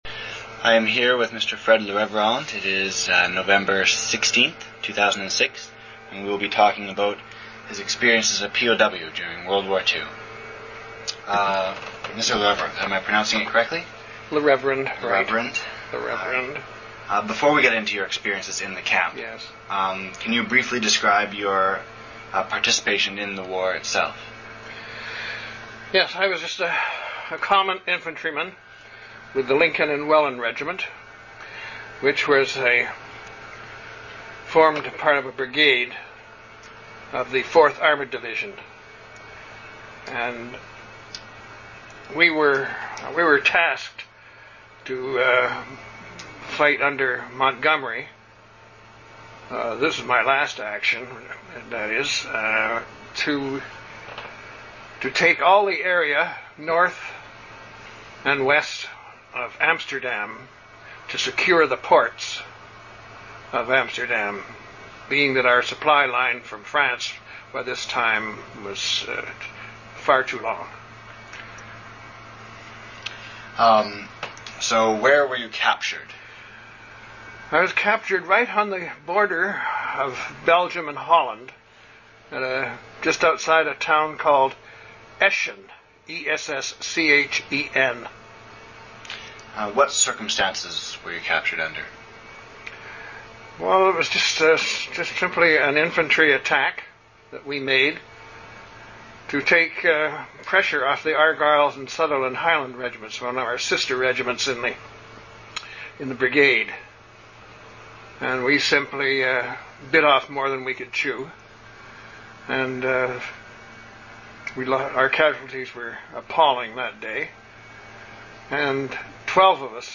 26:58 shut off tape to cut outside noise.
Library Genre reminiscences sound recordings oral histories (literary genre) interviews Archival item identifier LA_486 Fonds title Military oral history collection Fonds identifier SC141 Is referenced by Spe